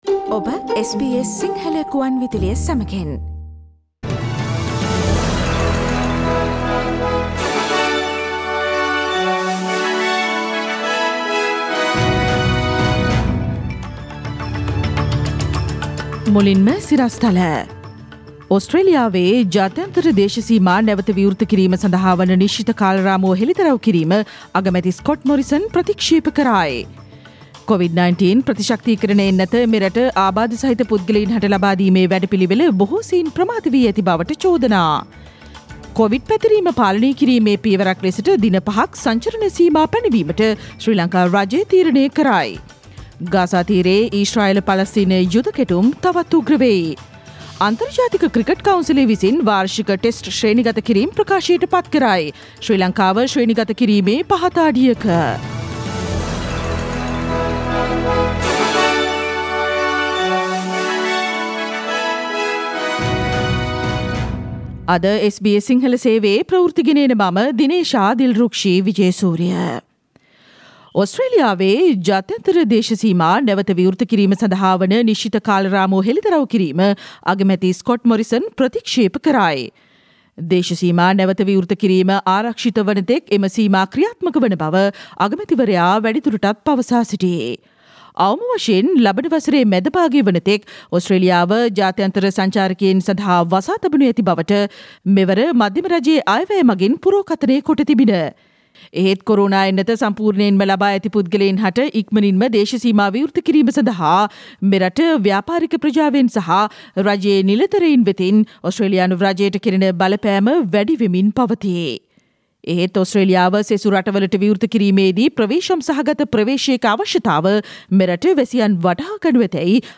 Listen to the latest news from Australia, Sri Lanka, across the globe and the latest news from sports world on SBS Sinhala radio news bulletin – Tuesday 18 May 2021.